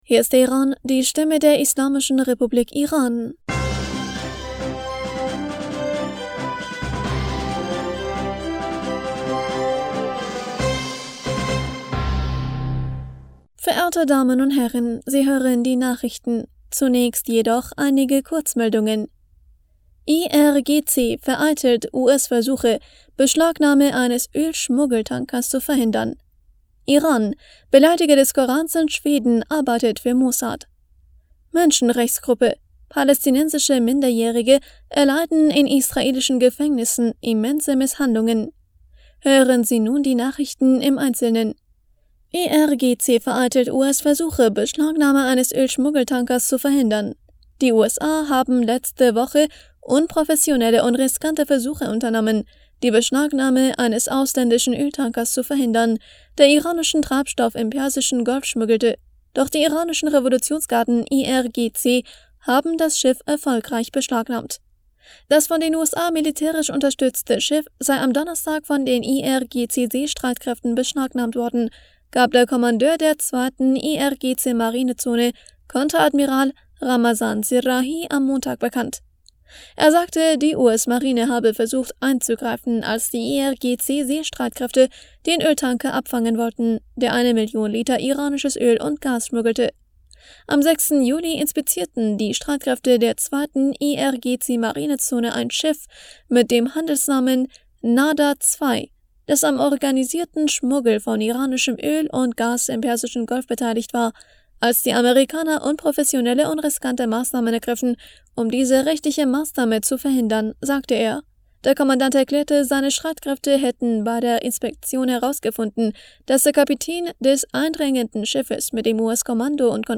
Nachrichten vom 11. Juli 2023
Die Nachrichten von Dienstag, dem 11. Juli 2023